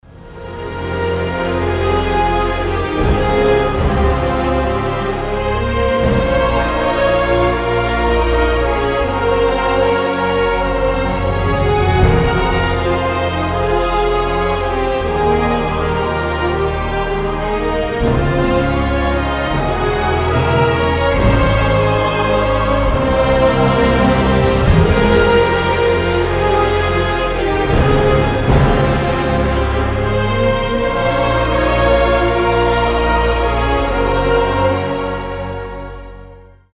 • Качество: 320, Stereo
красивые
без слов
печальные
оркестр